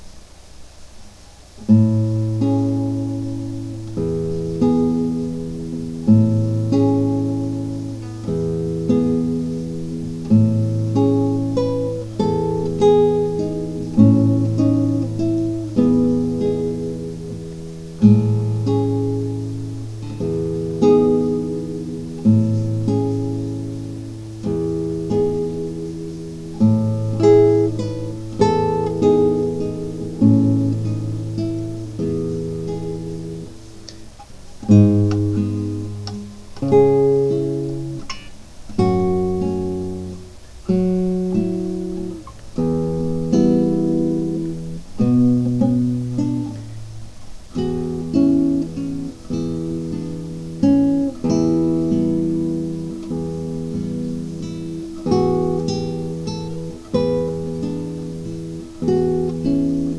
Outside, it's raining hard.
I pick up my guitar and play, accompanied by rainfall and thunder. The Almansa 436 sounds beautiful, harp-like. I make mistakes but keep the recording.
Listen carefully, and you might be able to hear the thunder and the rainfall in the background.